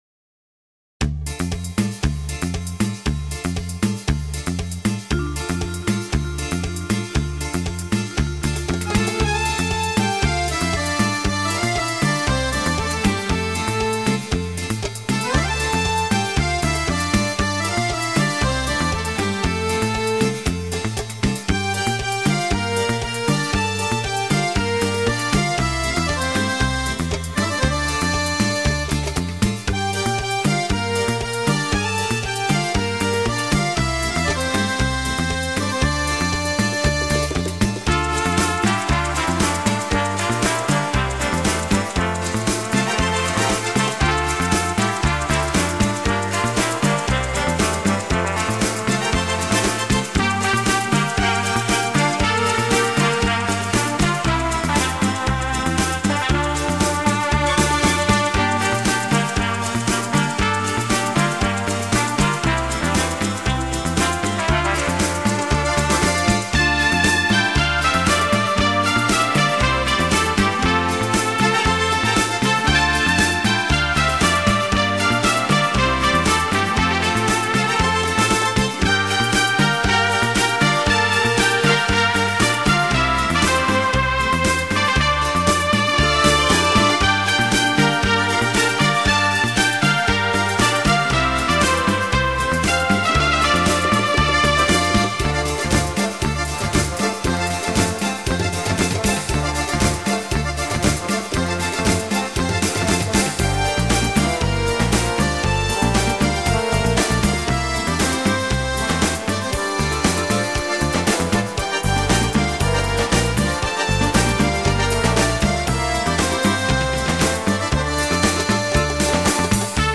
Ballroom, Easy Listening, World